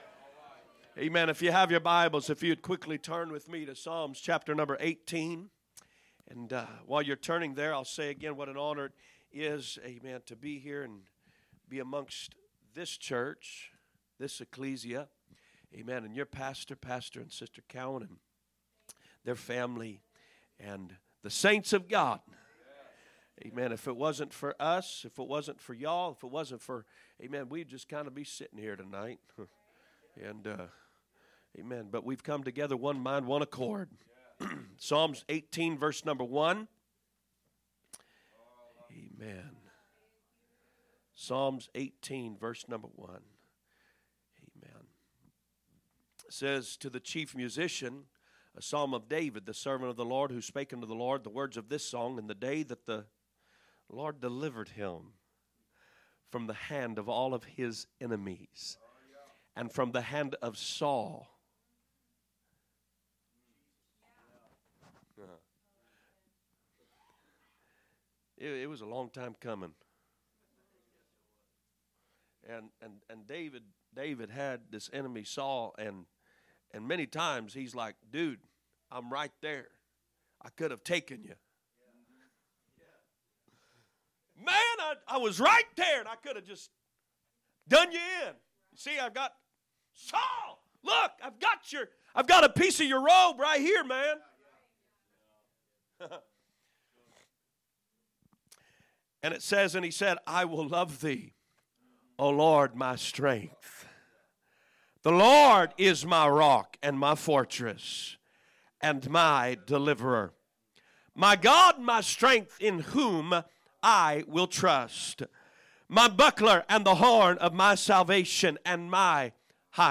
Thursday Message